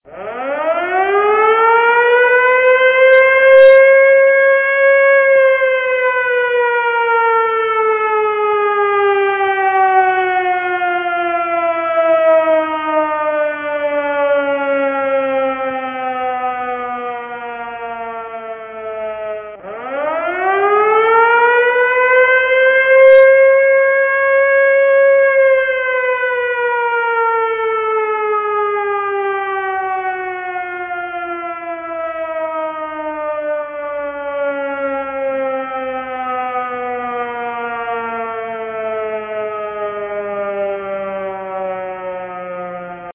Silent Hill - Siren.wav